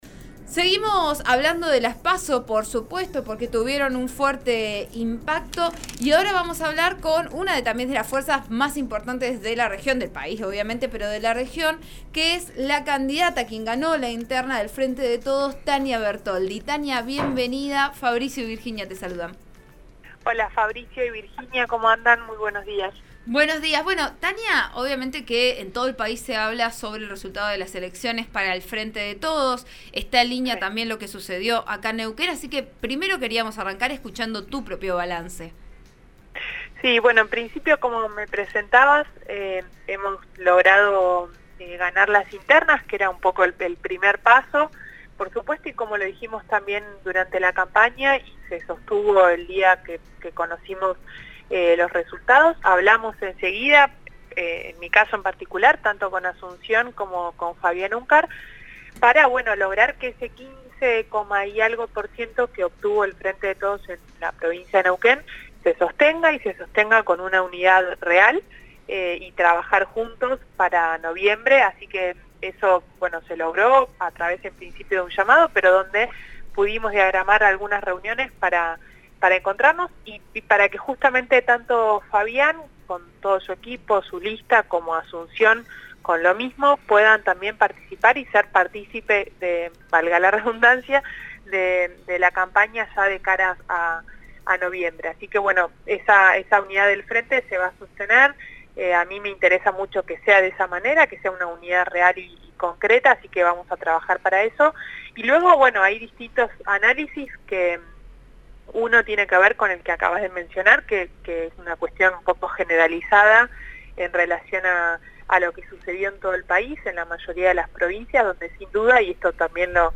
Esto se logró, en princpio, a través de un llamado y hemos diagramado reuniones para que ellos puedan participar con sus equipos de la campaña», indicó este martes en declaraciones a «Vos A Diario», el programa de RN Radio.